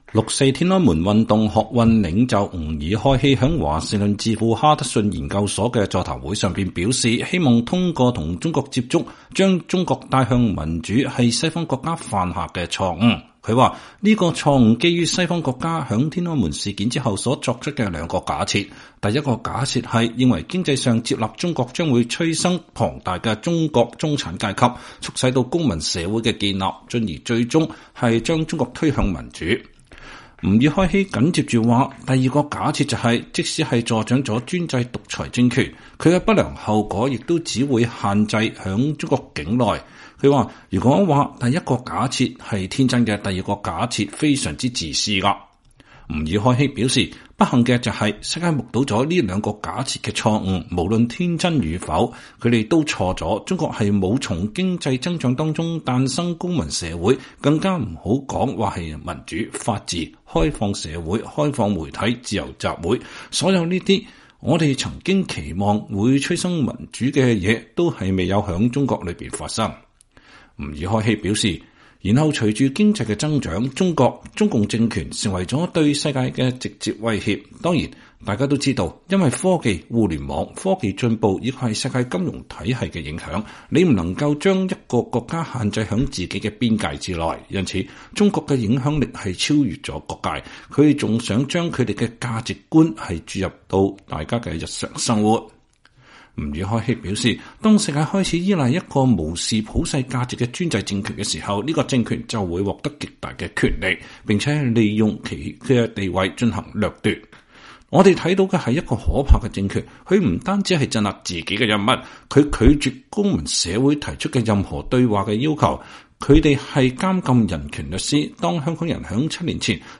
“六四”天安門運動學運領袖吾爾開希在華盛頓智庫哈德遜研究所的的座談上說，希望通過與中國接觸、將中國帶向民主是西方國家犯下的錯誤。